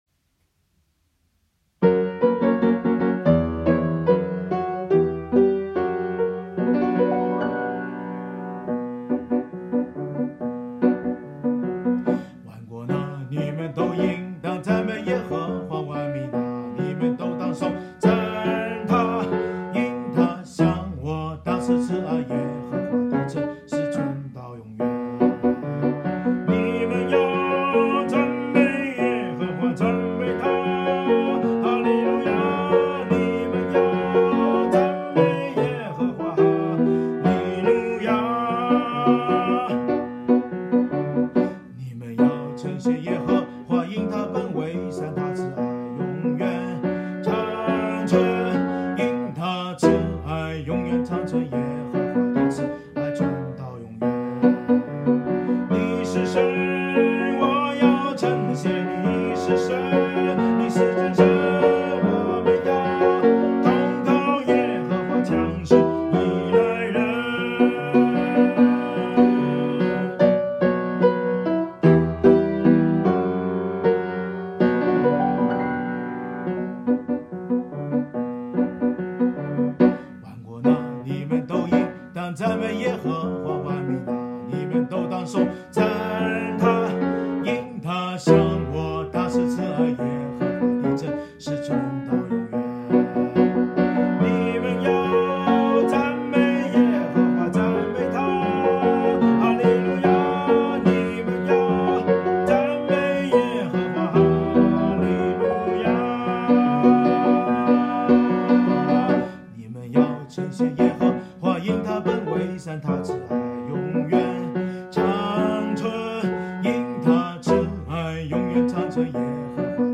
【圣经导读新唱】